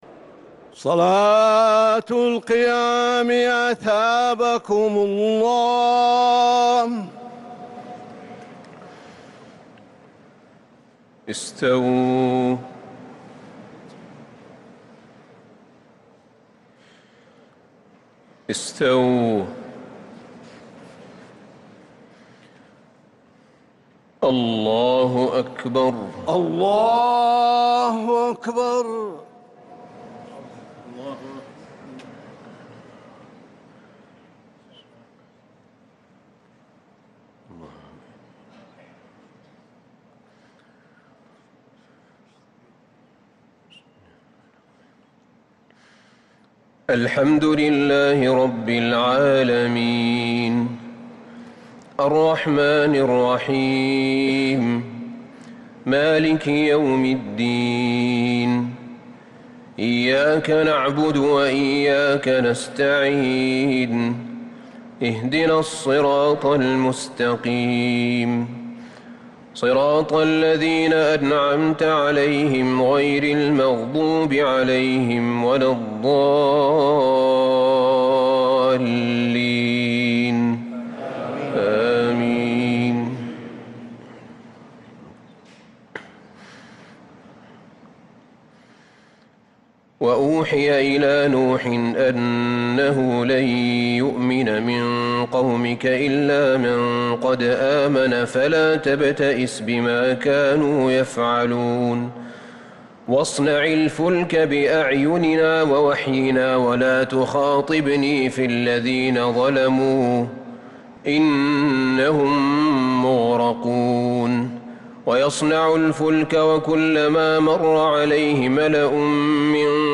تراويح ليلة 16 رمضان 1443هـ من سورة هود (36-123) | Tarawih prayer from Surat Hood 1443H > تراويح الحرم النبوي عام 1443 🕌 > التراويح - تلاوات الحرمين